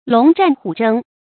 龙战虎争 lóng zhàn hǔ zhēng
龙战虎争发音